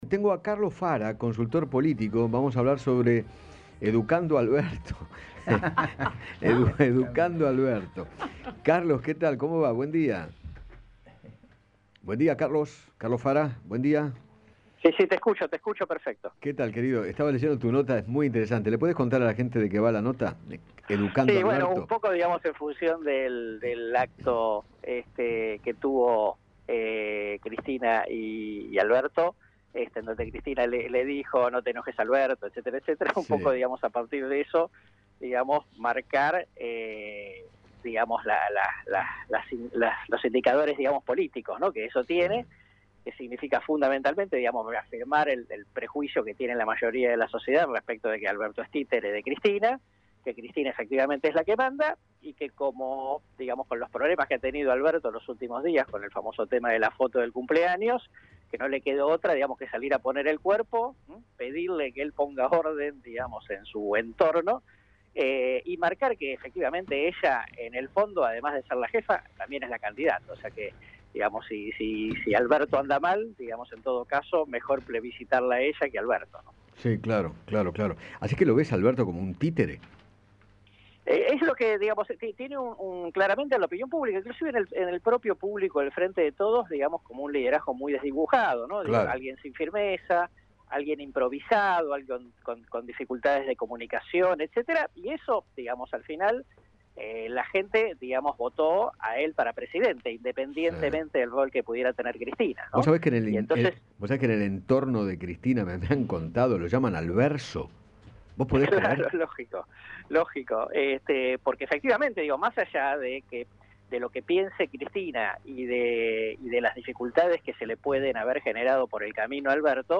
Carlos Fara, analista político, habló con Eduardo Feinmann acerca del lugar que ocupa Cristina Kirchner en el Gobierno y aseguró que “en el propio público del Frente de Todos se ve a Alberto con un liderazgo muy desdibujado”.